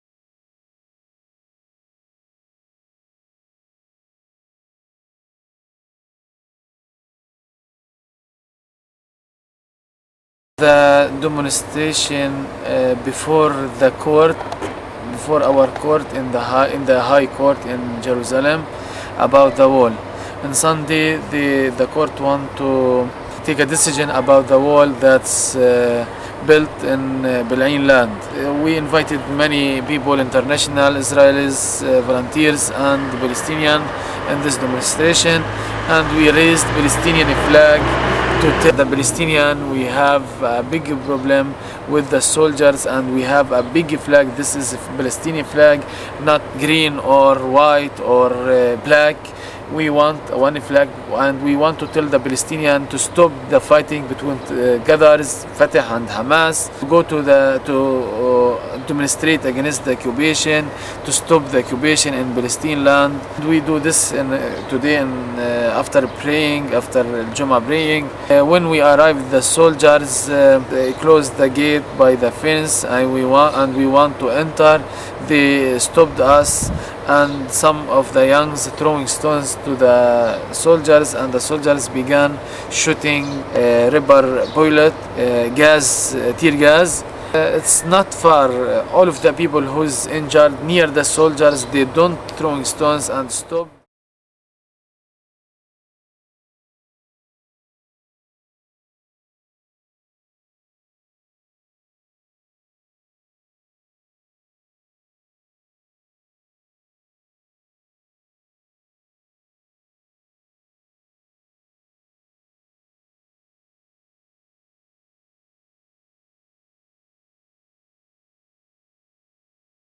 Audio report Israeli Army repression at nonviolent protests in occupied Bilien
Listen to an audio report which offering a soundscape and analysis of the Israeli Armies repression of the weekly non violent protest at the village of Bilien against the illegal Aparthied wall annexing the village from its agricultural lands. Over the weekend settlers from the near by illegal settlement torched the village's outpost designed to protest at the theft of Be'lin 's farm lands.